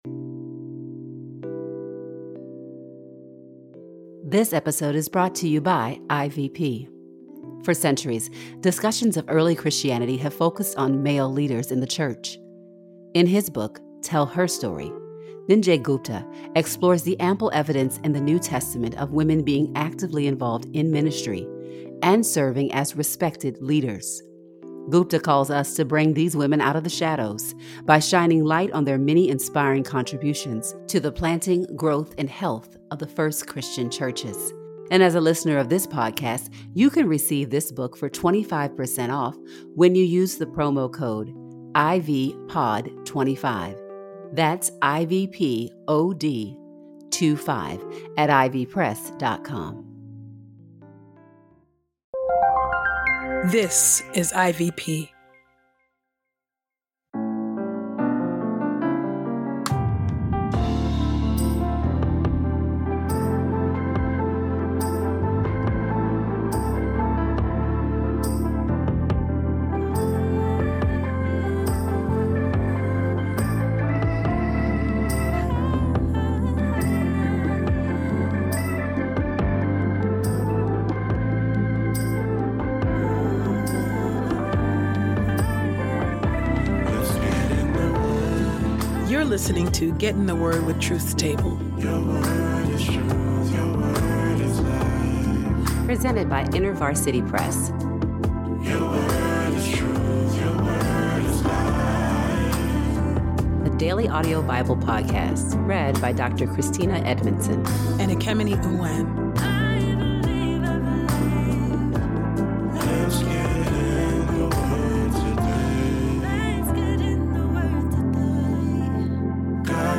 a daily Bible podcast narrated